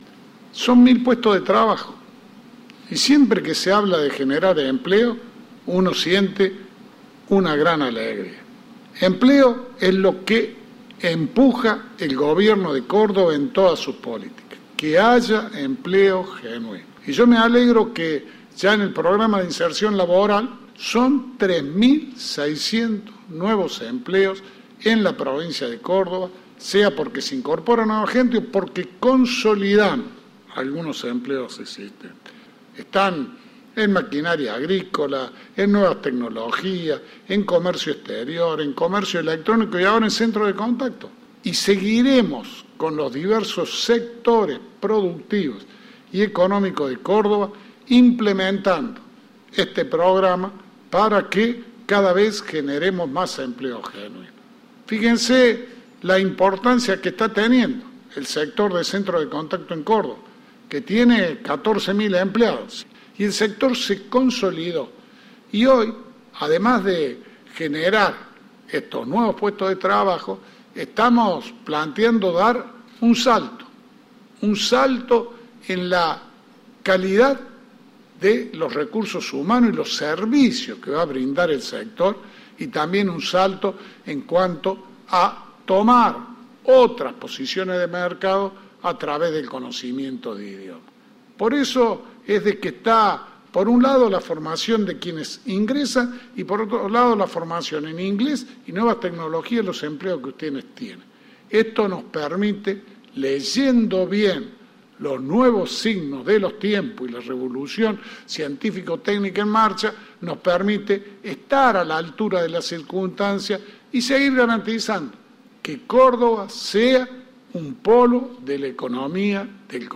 El gobernador Juan Schiaretti, junto a la ministra de Promoción del Empleo y de la Economía Familiar, Laura Jure y al ministro de Industria, Comercio y Minería, Eduardo Accastello; presentó este lunes el Programa de Inserción Laboral (PIL) en Centros de Contacto y Procesos de Negocios.